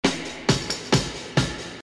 Avec un seul échantillon de  5 battements il est possible de reproduire la batterie du morceau entier en les enchaînant simplement avec un éditeur de .WAV !
Les deux derniers battements de l'échantillon correspondent aux deux premiers Si de la deuxième phrase de la guitare.